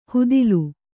♪ hudilu